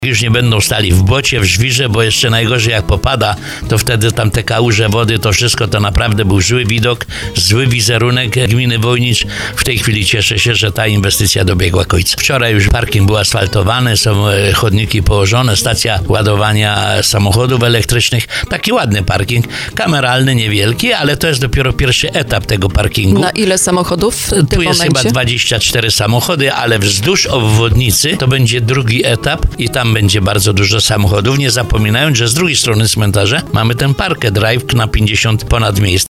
Jak podkreśla burmistrz Wojnicza, Tadeusz Bąk, inwestycja została zrealizowana w samą porę: